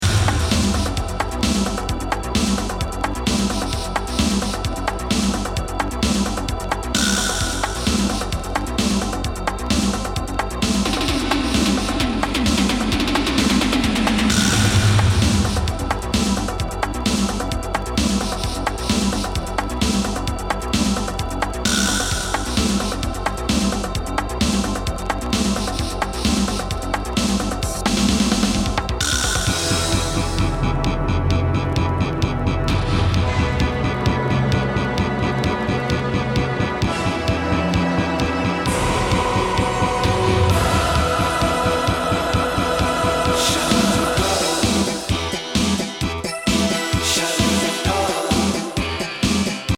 和コズミック